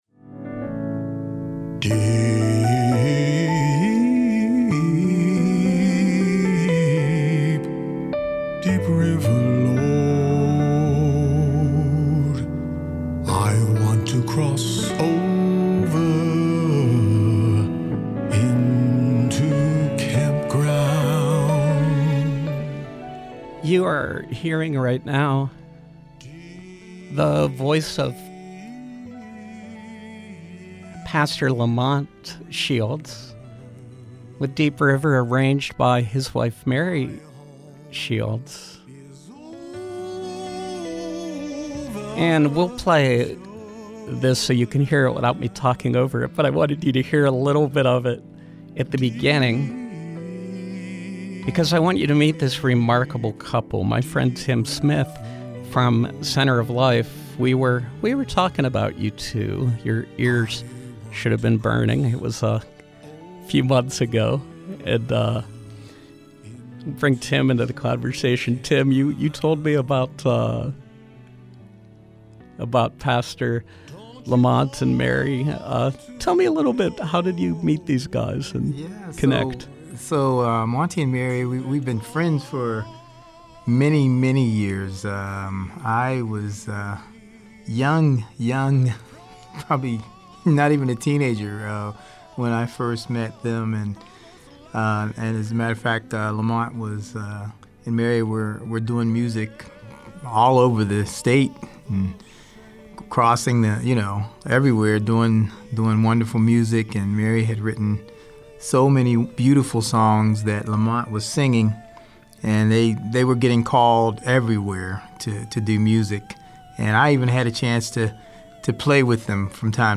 Gospel singing/songwriting couple